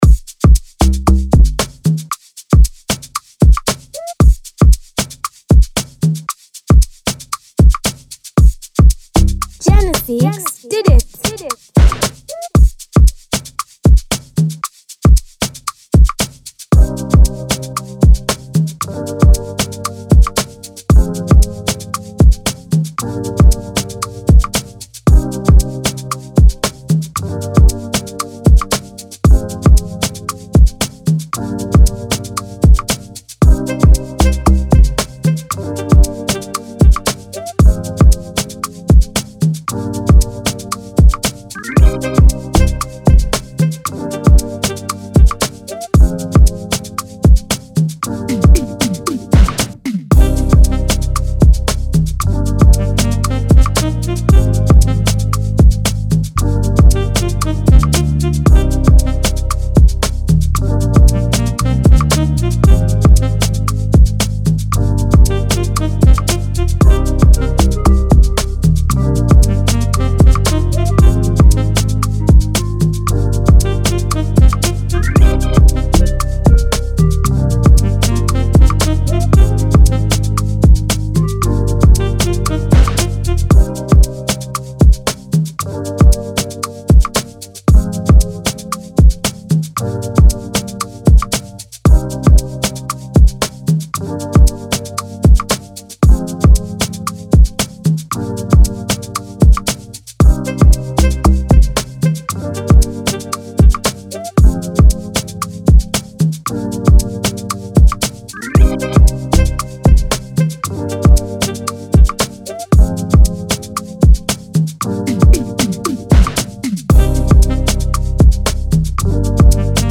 Amapiano instrumental beat